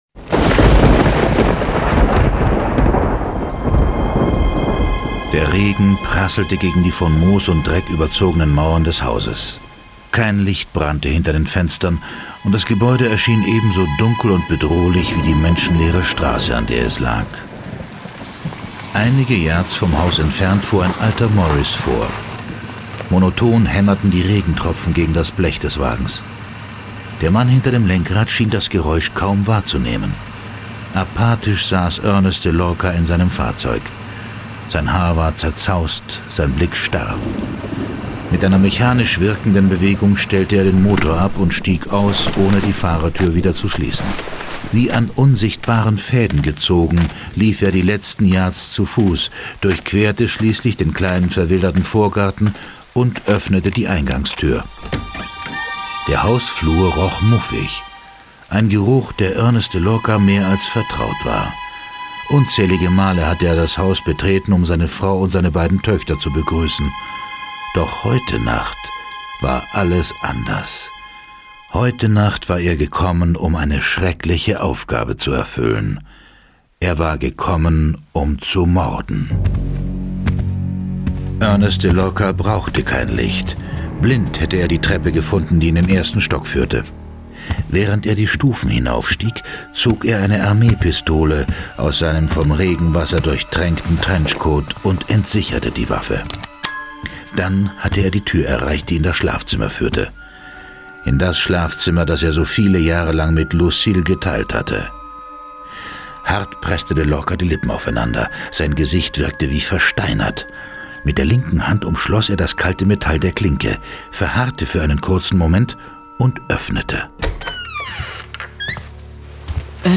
John Sinclair - Folge 4 Damona - Dienerin des Satans. Hörspiel.